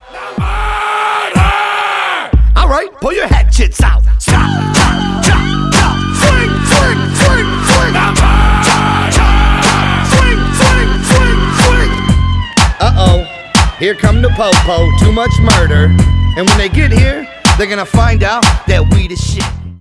bass boosted
рэп
хип-хоп